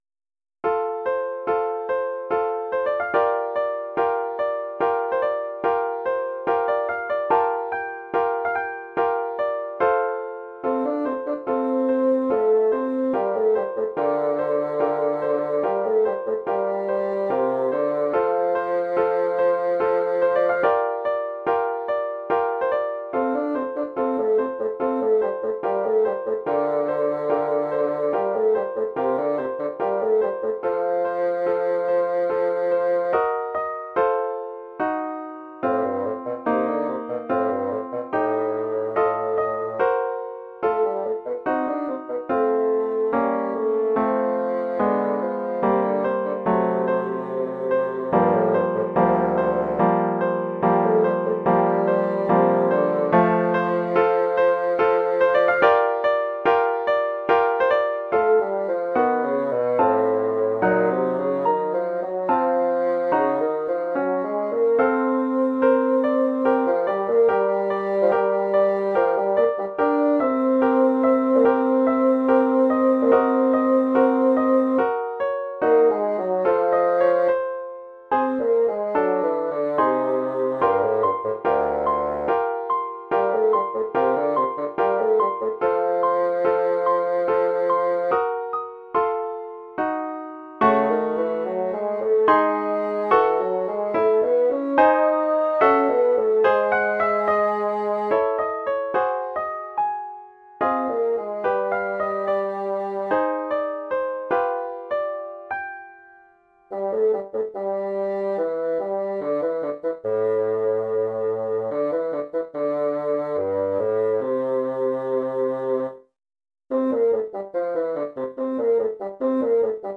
Oeuvre pour basson et piano.